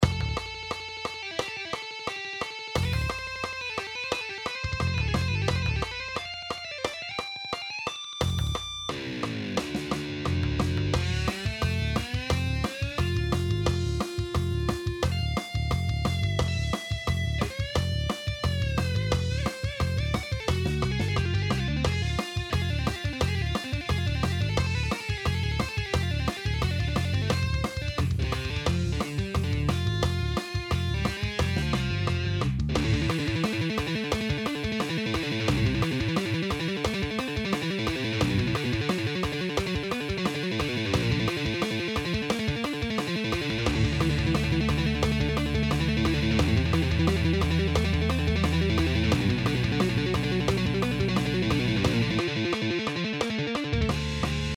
If you want to hear this solo only, here it is: